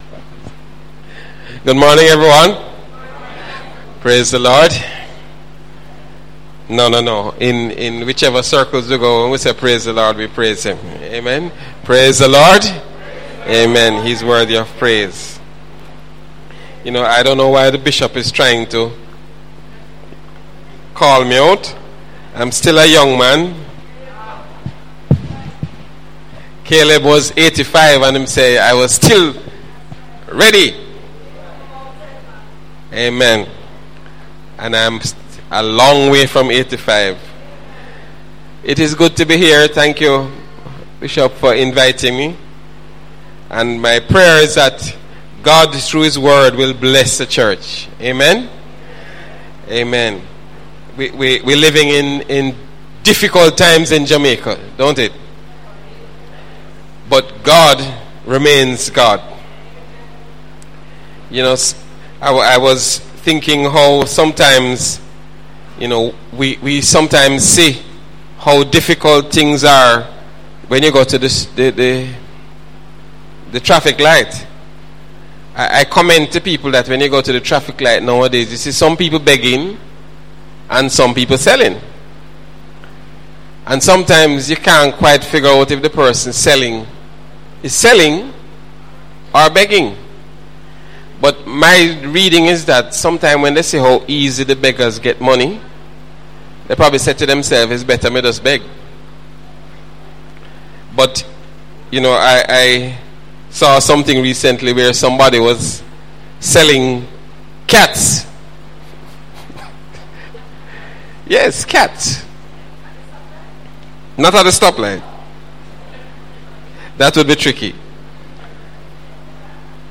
Sunday Sermon – Do Not Give Into Discouragement – Jan. 28, 2018